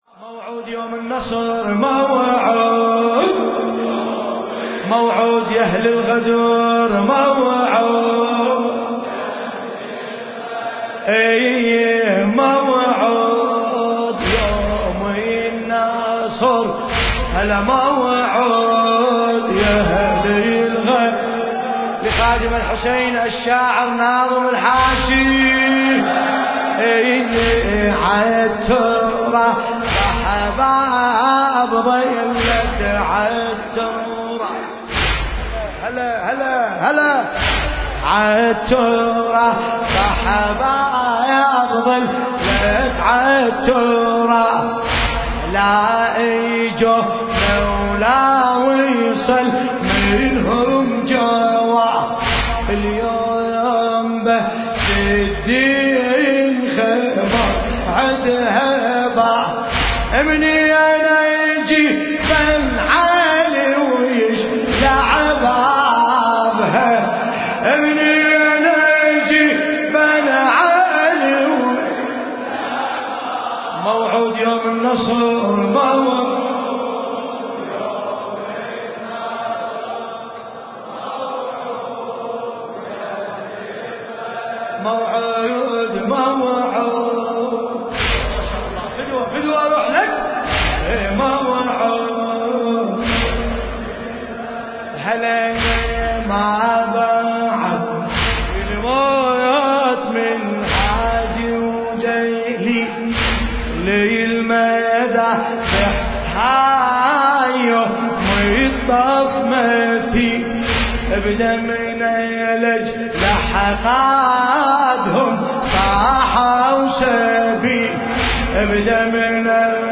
المكان: حسينية المرحوم داود العاشور- البصرة